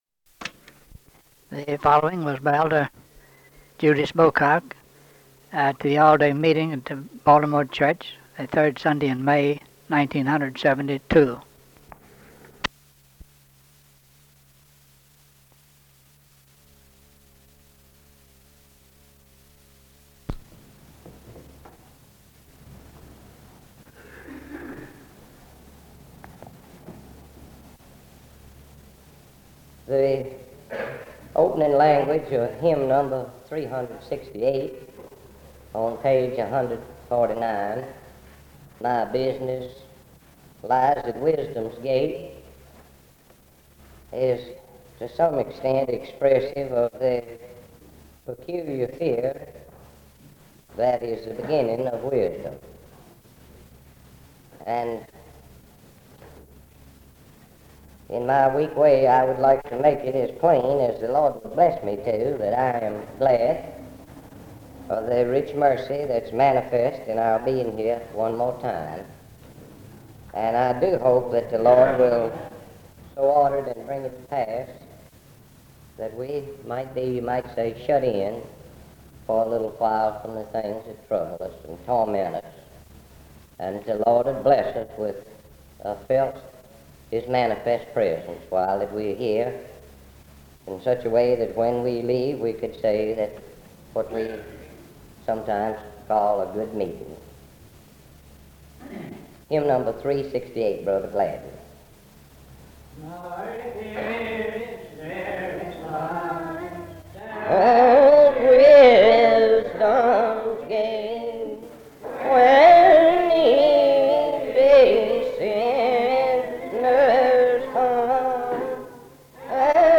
Sermon
at a Baltimore all-day meeting